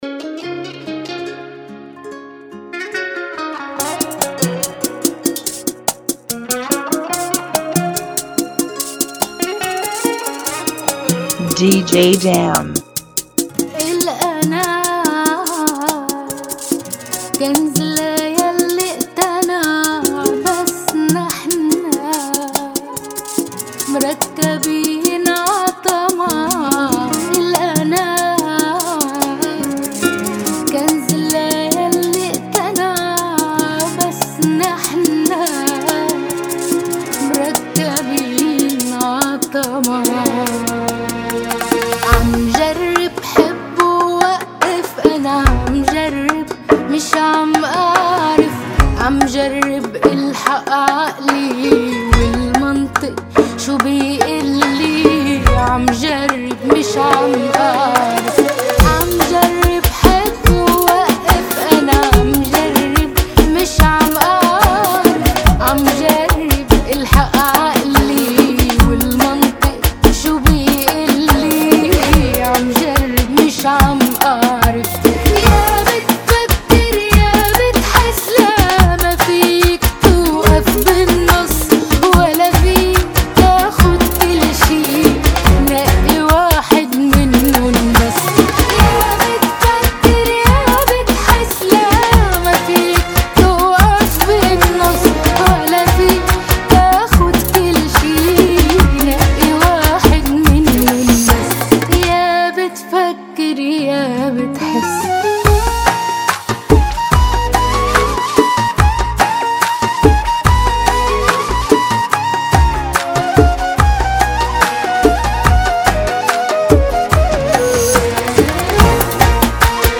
144 BPM
Genre: Bachata Remix